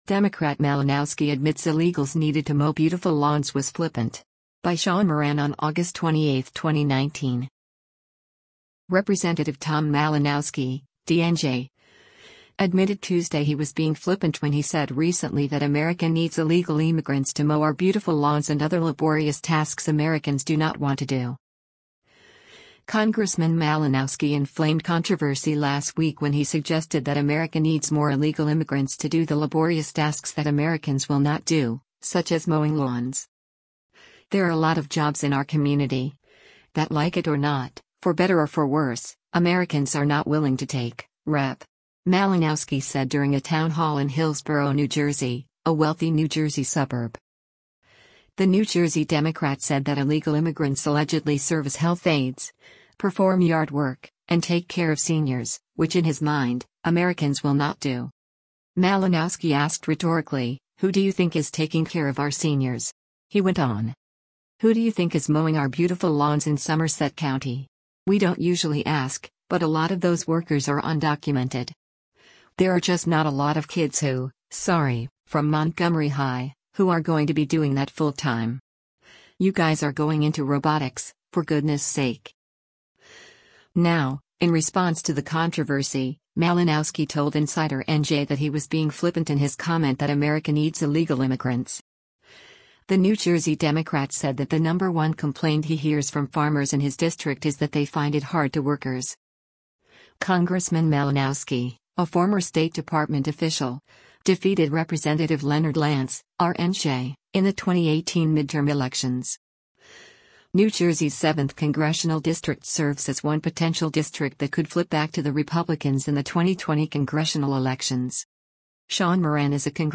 “There are a lot of jobs in our community, that like it or not, for better or for worse, Americans are not willing to take,” Rep. Malinowski said during a town hall in Hillsborough, New Jersey, a wealthy New Jersey suburb.